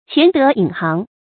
潛德隱行 注音： ㄑㄧㄢˊ ㄉㄜˊ ㄧㄣˇ ㄒㄧㄥˊ 讀音讀法： 意思解釋： 謂不為人知的德行。